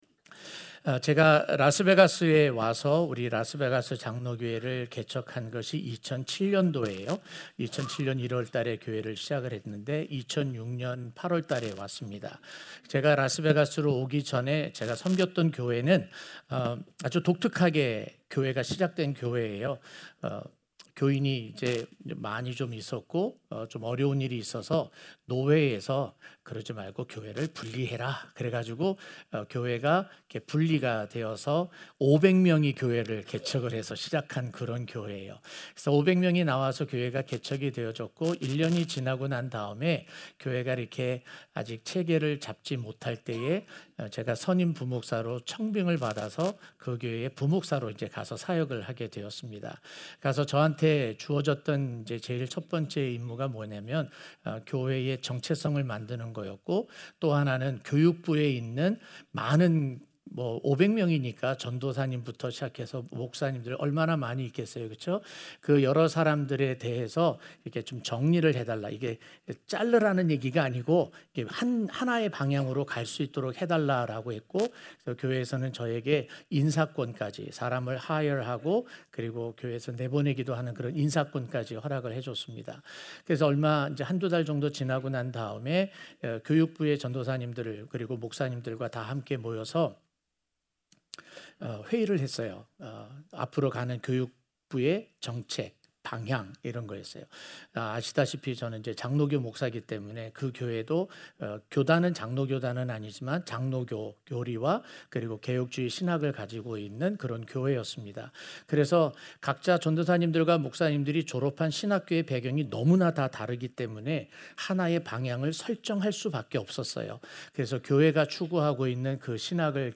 03-A-Limited-Atonement-1-Sunday-Morning.mp3